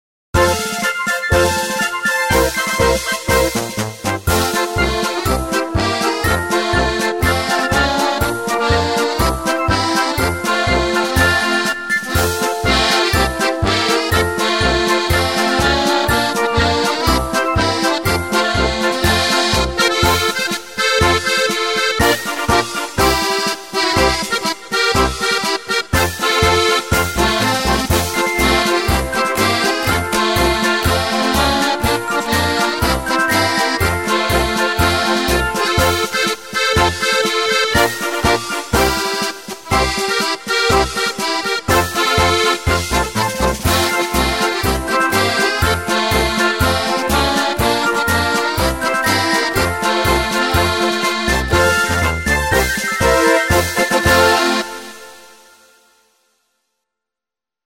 Deutscher Marsch 1
deutscher marsch 1 demo.mp3